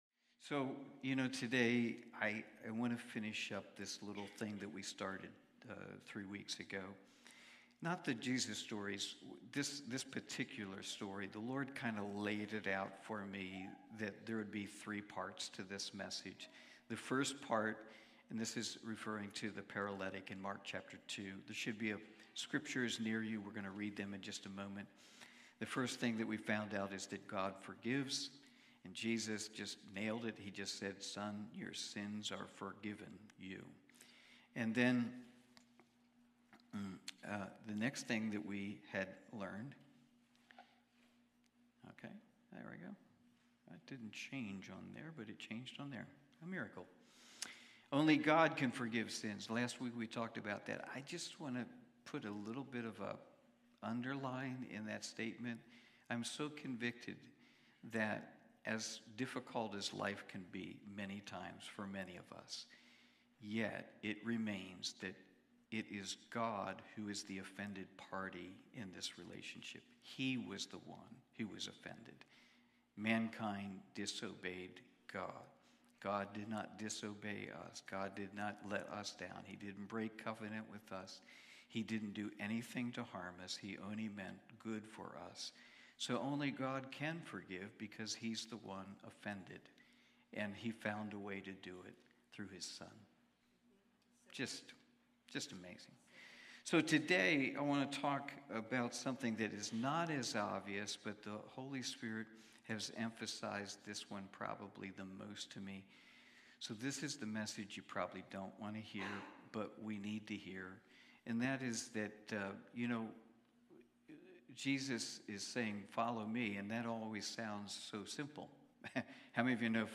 Sunday morning service, livestreamed from Wormleysburg, PA.